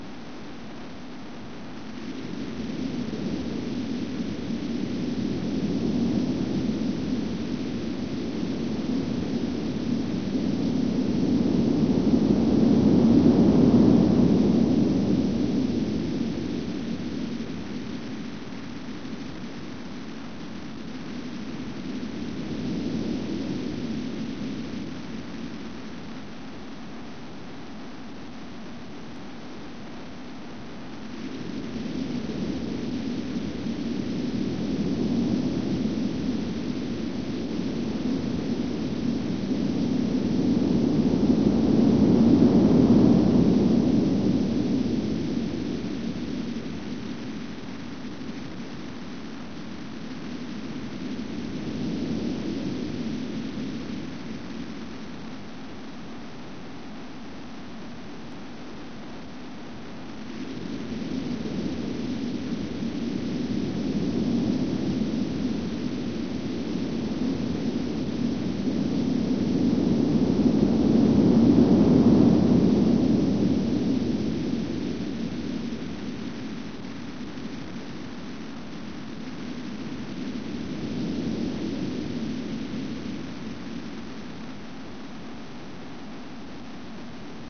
Type BGM
Speed 60%